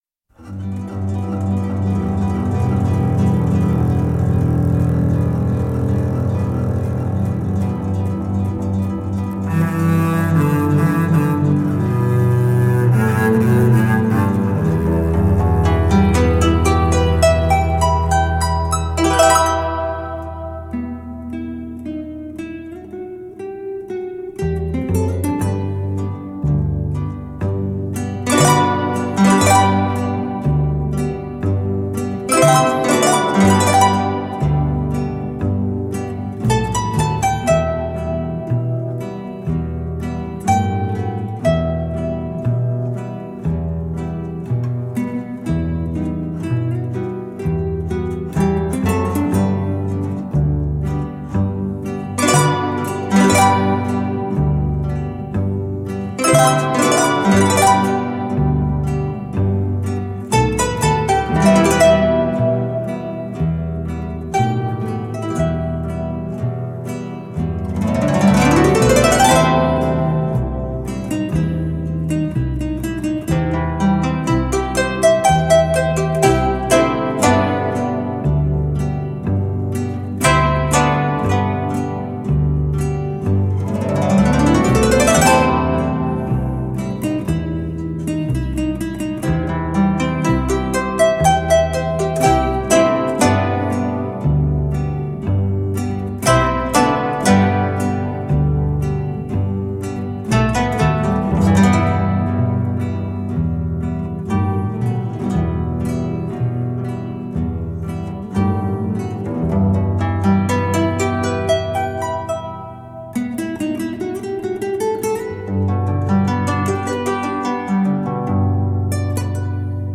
錄音師全新數位技術混音，還原母帶原聲音效